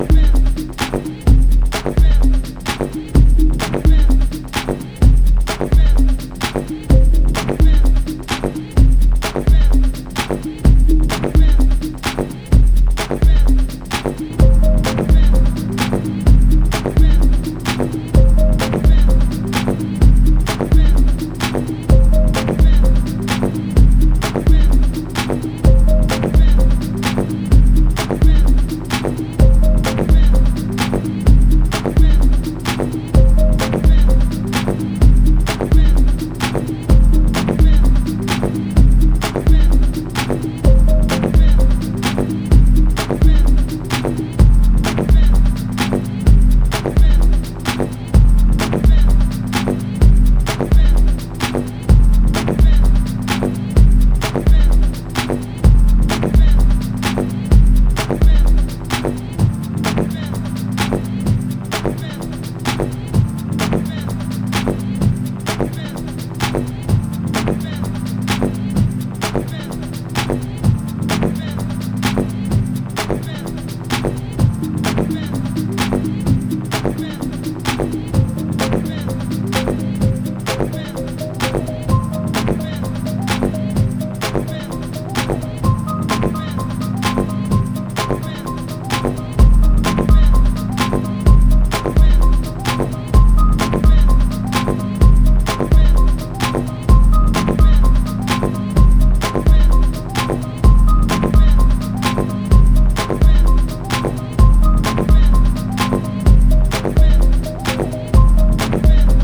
blending analog, organic, and electronic sounds